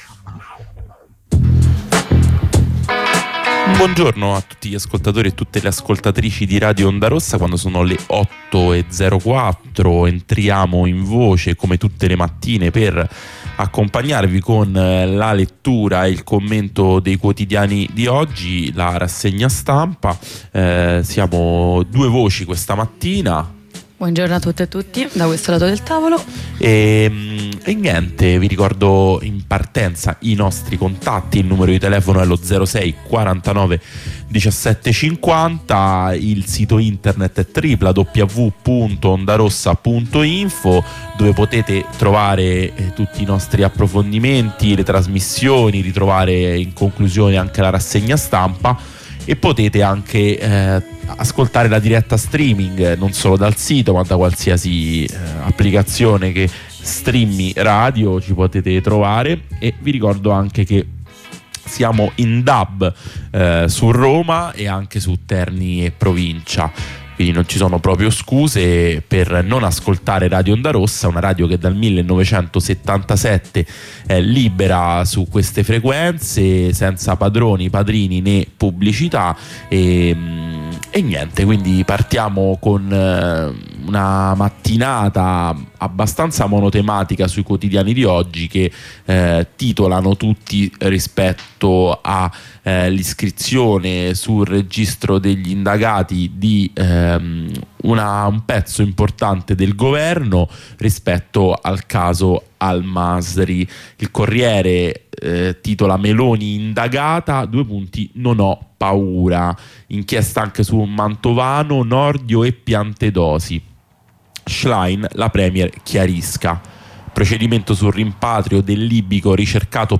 Lettura e commento dei quotidiani.
Rassegna stampa